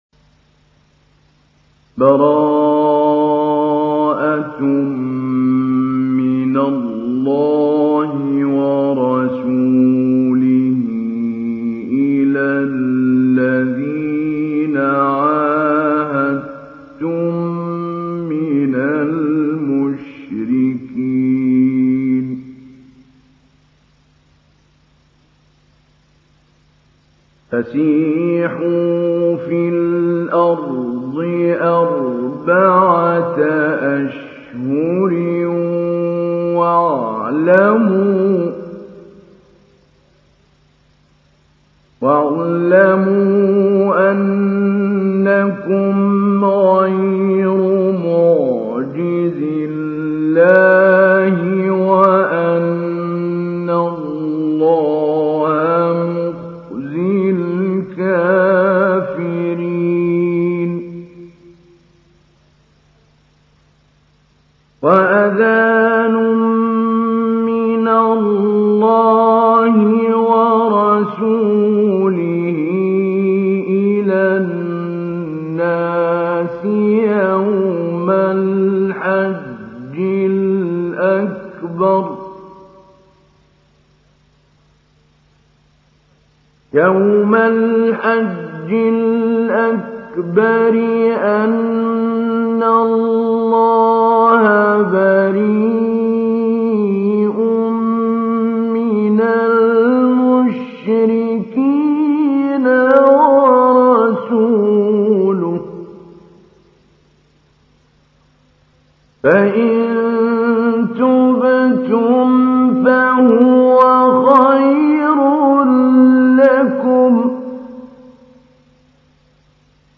سورة التوبة | القارئ محمود علي البنا